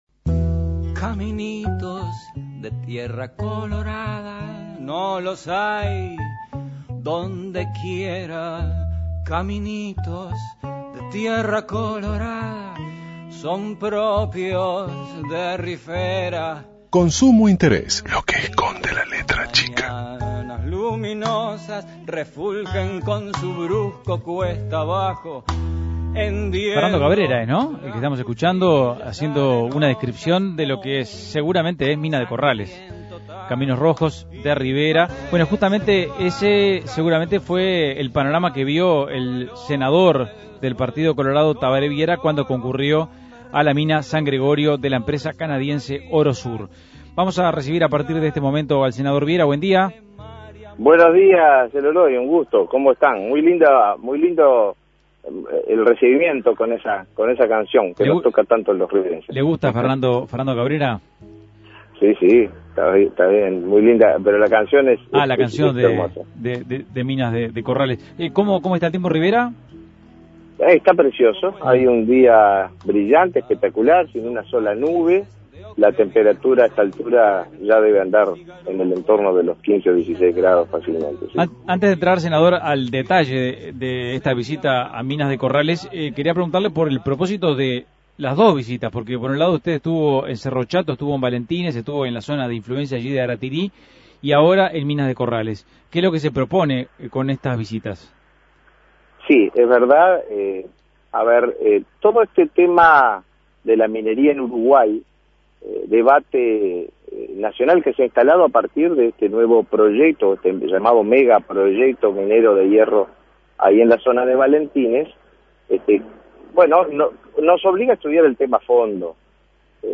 Entrevista a Tabaré Viera